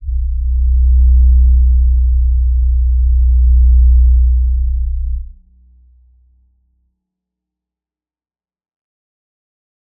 G_Crystal-B1-pp.wav